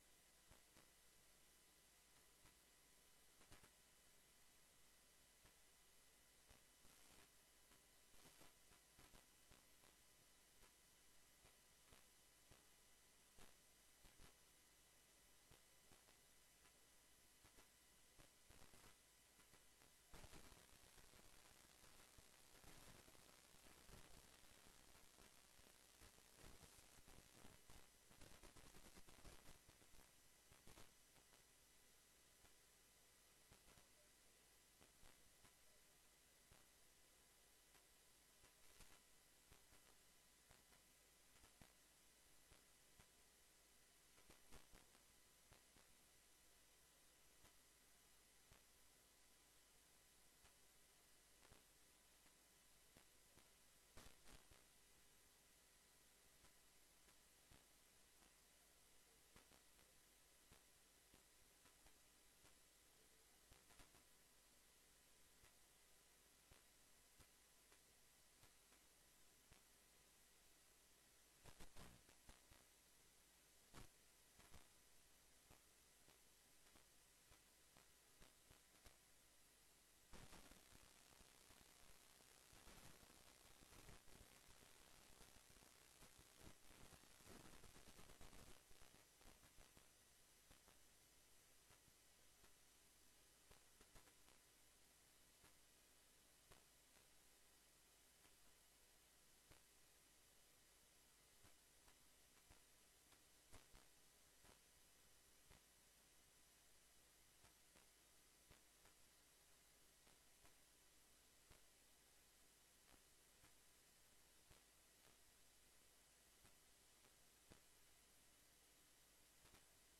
Wethouder Winters beantwoordt ter vergadering de volgende vragen:- Uit de raadsinformatiebrief van 1 april jl. over de vervangingsinvestering groen blijkt dat sommige buurten pas in 2030 aan de beurt zijn, terwijl het nodig is dat dat eerder gebeurt.
Locatie: Raadzaal (Audio)